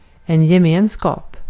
Uttal
Synonymer förbindelse kommun samvaro samhälle Uttal Okänd accent: IPA: /jɛˈmeːn.skɑːp/ Ordet hittades på dessa språk: svenska Översättning 1. topluluk Artikel: en .